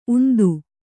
♪ undu